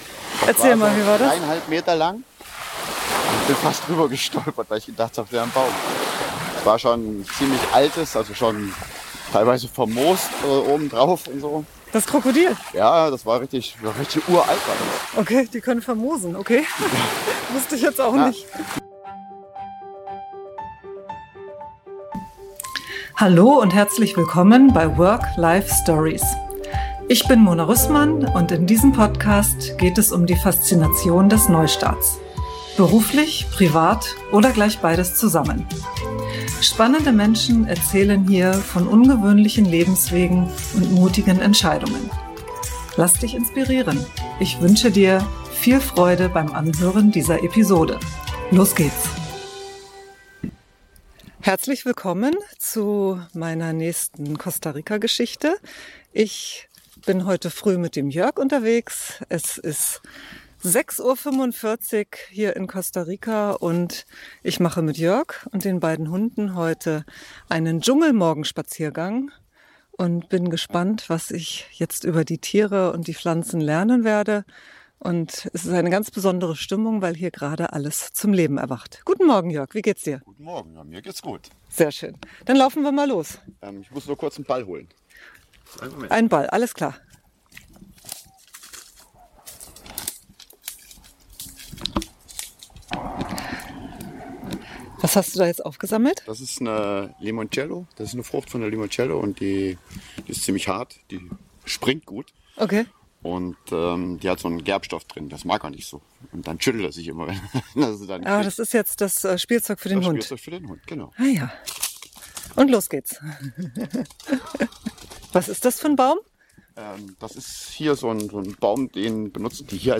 Fauna, Flora und Costa Rica Insights mit Meeresrauschen und Dschungelsound.